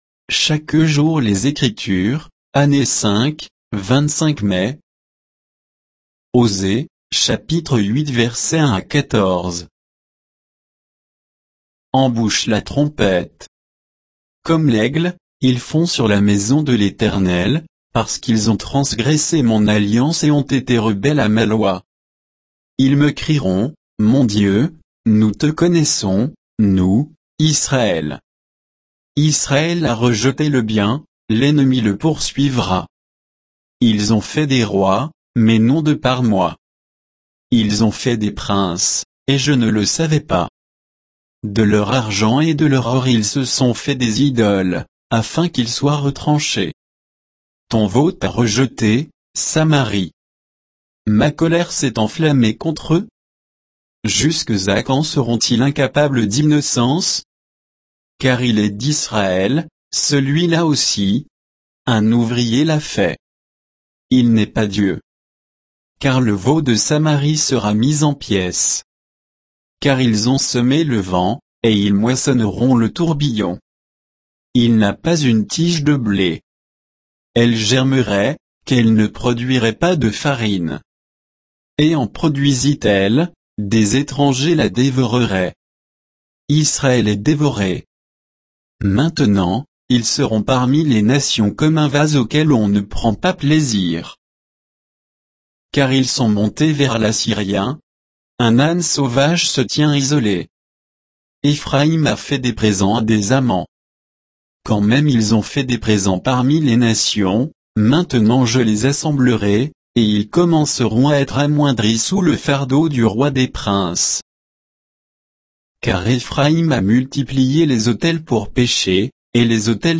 Méditation quoditienne de Chaque jour les Écritures sur Osée 8, 1 à 14